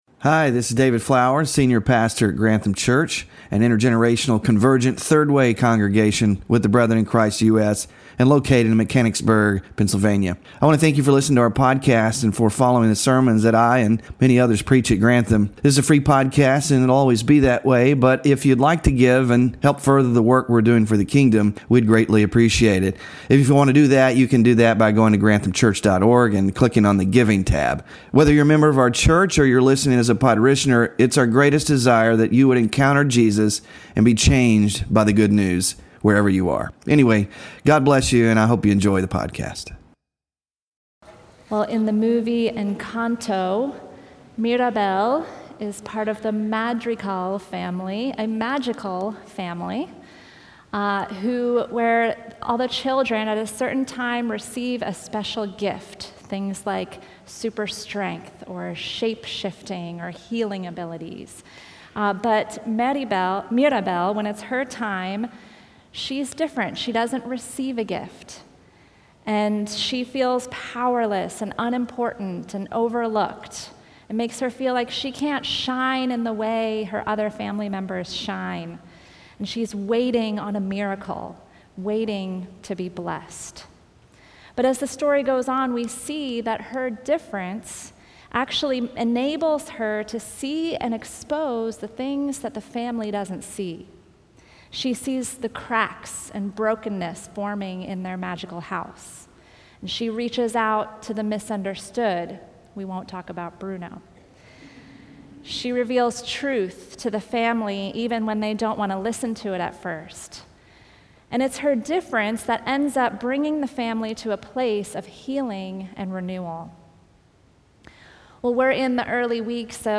Scripture Reading: Matthew 5:13-16